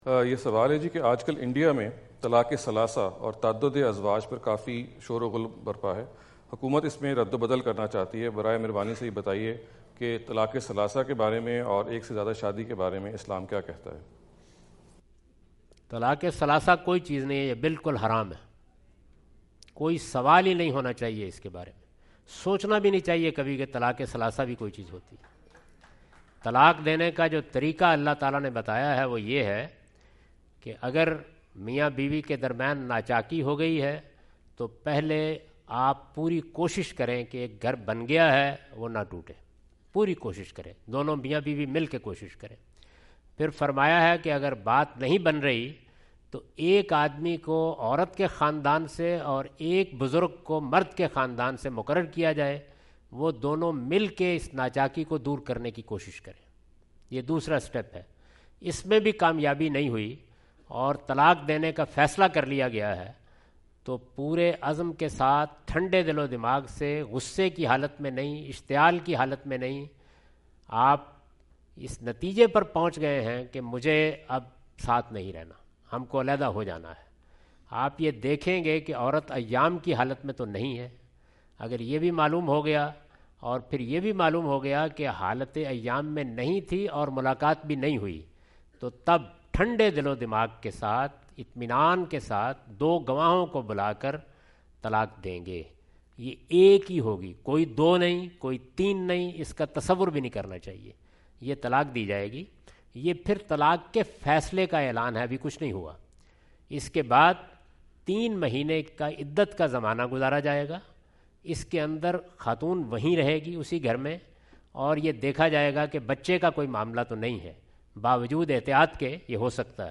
In this video Javed Ahmad Ghamidi answer the question about "divorcing thrice at the same time?" asked at Chandni Restaurant, Newark, California on October 21,2017.